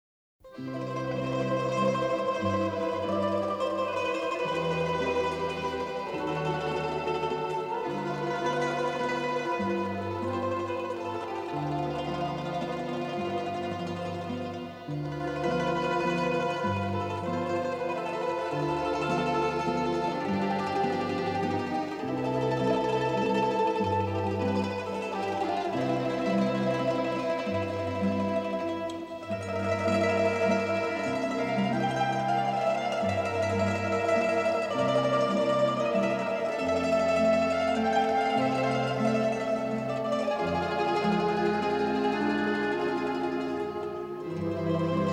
a melodic slice of "Italiana,"